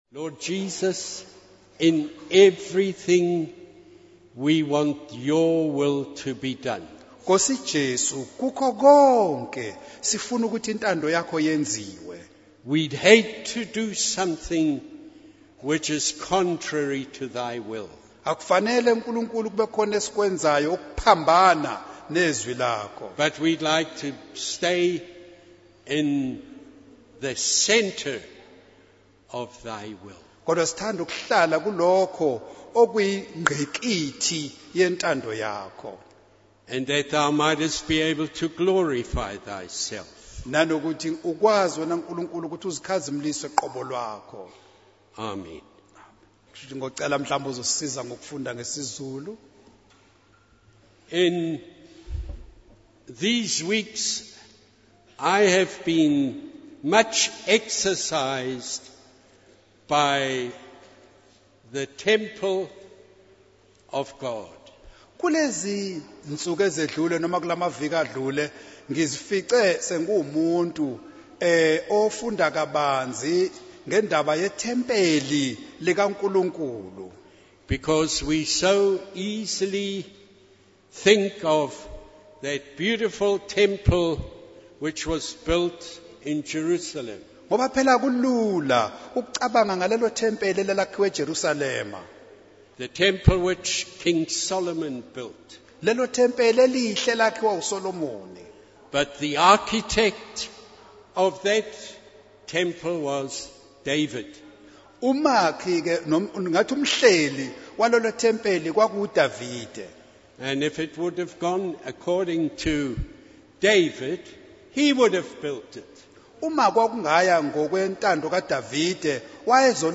In this sermon, the speaker shares a powerful analogy of a dying bee that was revived by being given sugar water. He relates this to the spiritual hunger and thirst that people have, and how it is our responsibility to provide them with the living water of God's word.